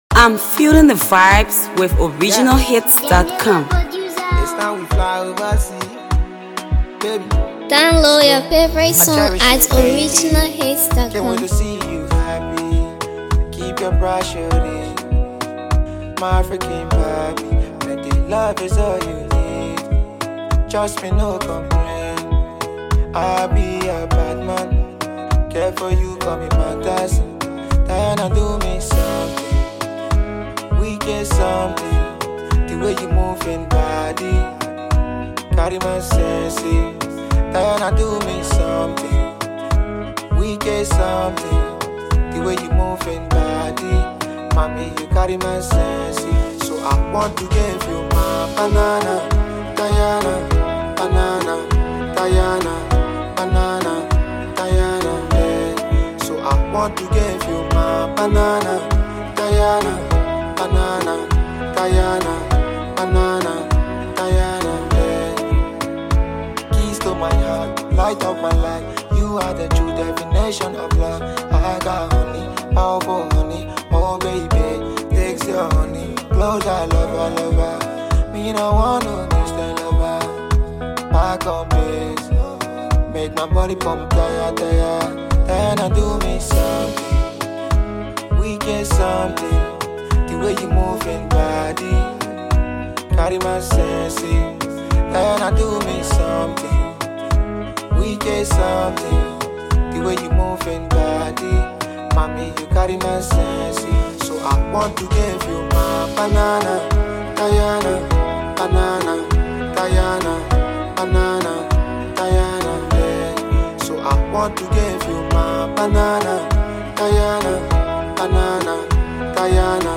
catchy melody banger